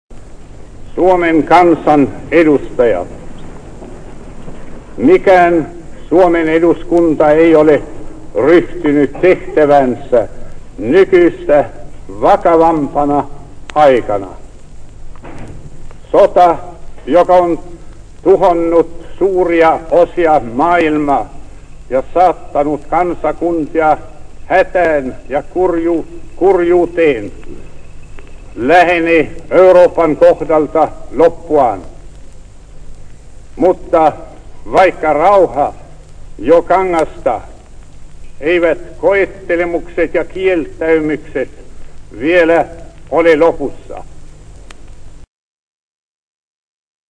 Antrittsrede von Svinhufvud nach seiner Wahl zum Präsidenten der Republik.